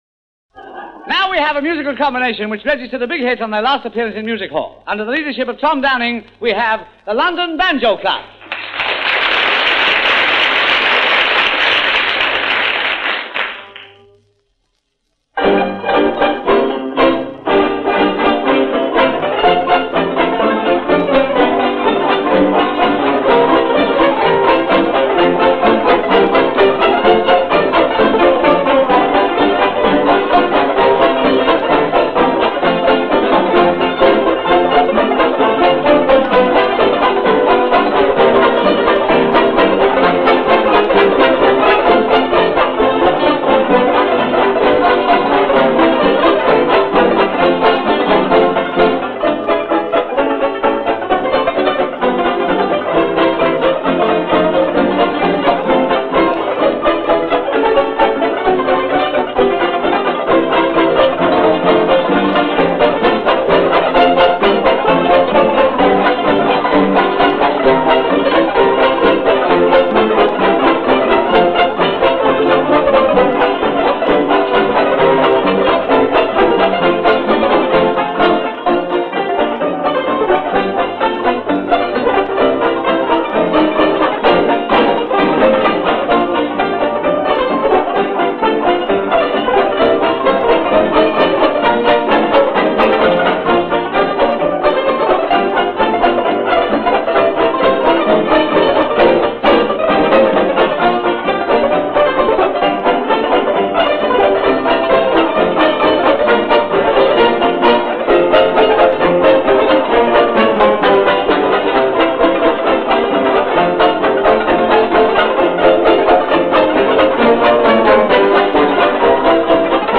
orchestral textures of the banjo